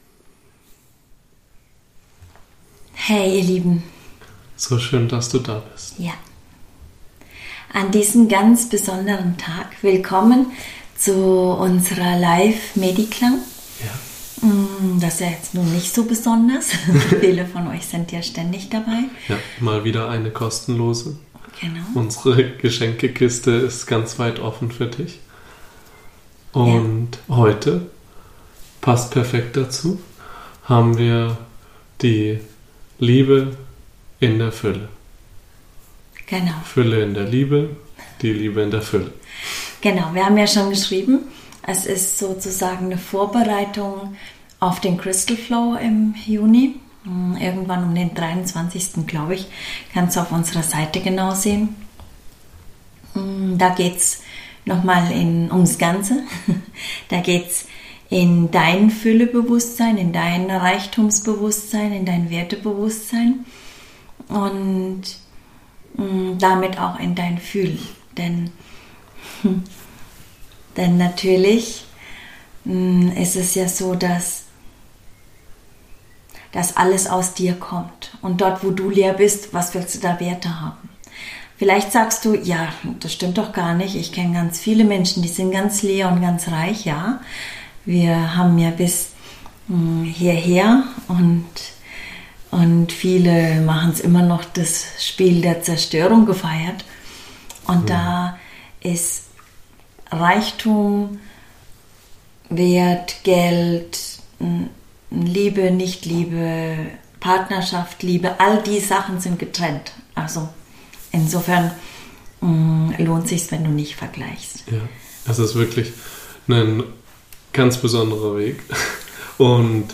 MediKlang | Meditation und Klang ~ MenschSein - musst du leben.